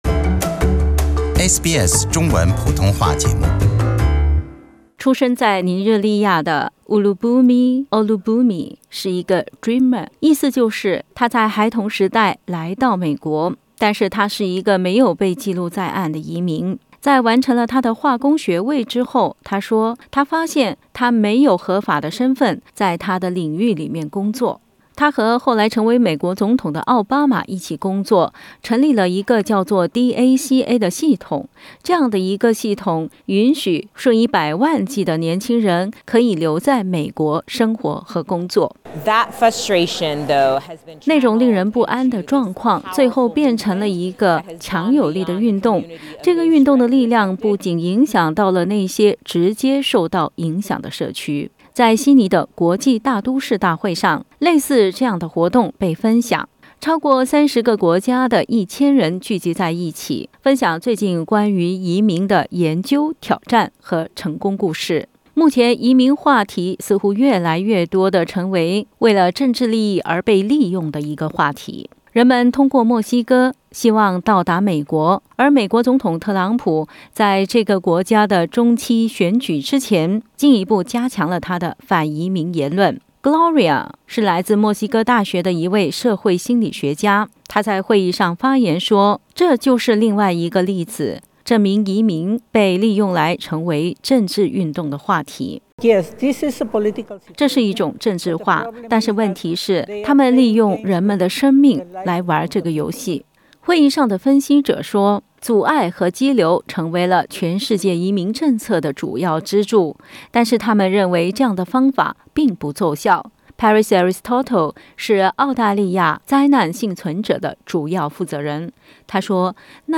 在悉尼举行的国际大都市年会上，来自超过30个国家的1000名与会者就移民和难民话题展开讨论。 发言者提出，不应该拿生命来玩政治游戏。 会议关注难民不得不为了保护自己和家人，逃离战乱和困境的事实，要求国际社会不要用简单的阻遏和羁留的方式来对待难民，而是重新思考新的方式。